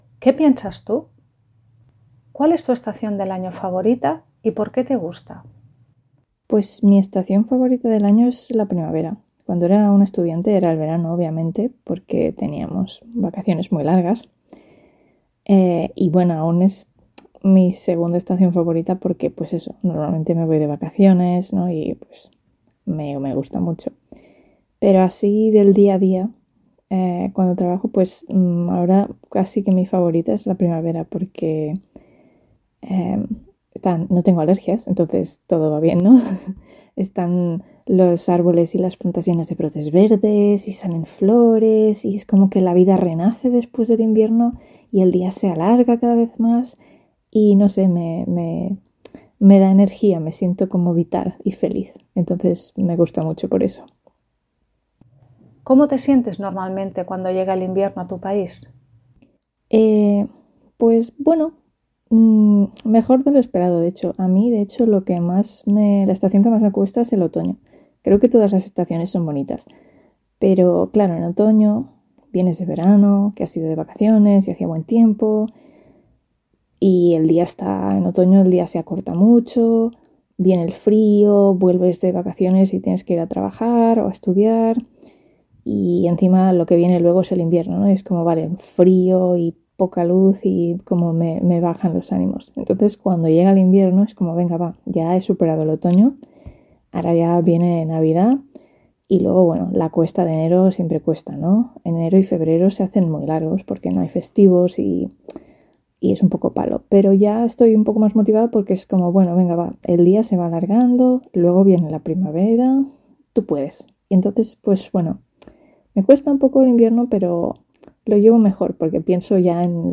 Ahora escucha a un nativo que contesta las preguntas.